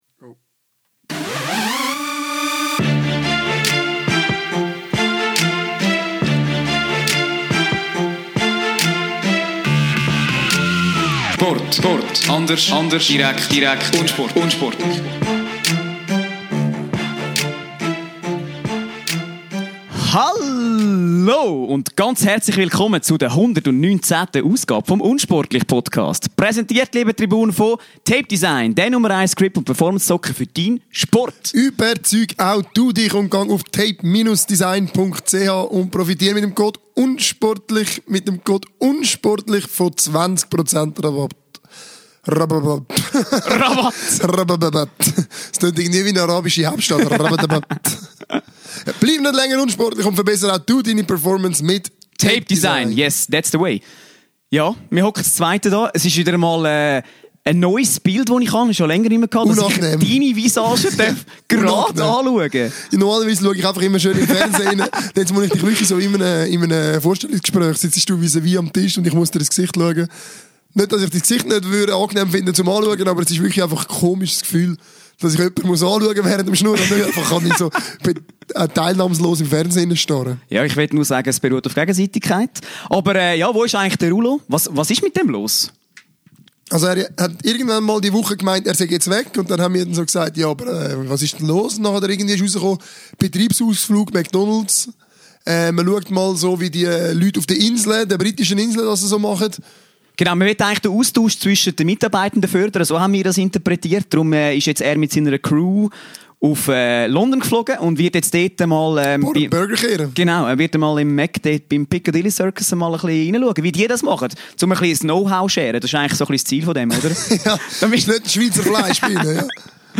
Zudem machen wir einen wirklich nur kurzen und knackigen Ausflug zur Tour de France und widmen uns dafür um so intensiver dem unglaublichen Wechsel-Karrussel in der NBA! Stargast Zlatan Pferdeschwanz Ibrahimovic klärt uns einmal mehr wieder auf, wer in der Major League Soccer der Beste ist!